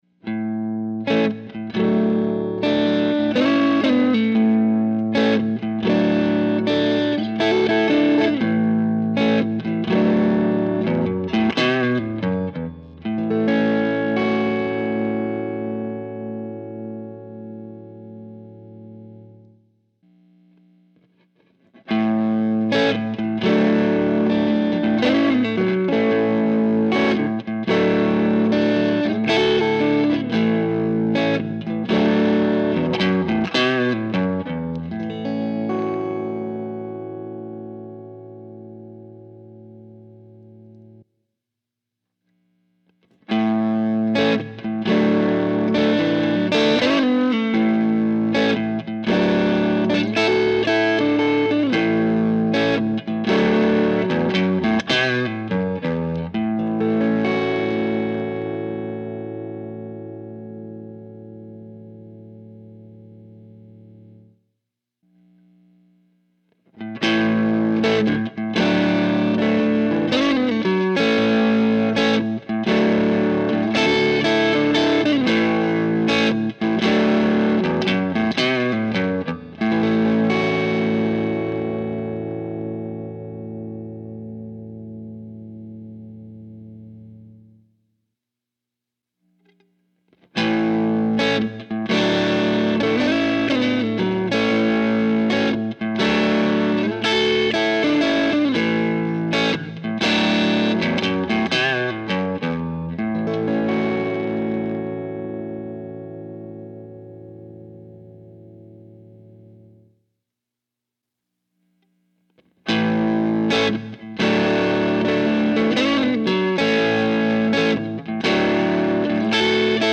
The clips are level matched, starting with the PRX150-Pro bypassed and then going click by click all the way down to max attenuation, then back to bypassed at the very end. There is a distinct pause between each riff/click. Recording chain was Royer 121 about a foot from the grill into Great River MP2NV, there is a high pass filter at 30hz and a tiny bit (like 5% mix) of reverb from an IK plugin.
Clip 1: EJ Strat on neck pickup into a 35w Embassy which has an AC30 based preamp into 4x6V6s in Class A. Speaker cab is an open back 2x12 with Scumback H55 and Celestion V30, the H55 is the one recorded.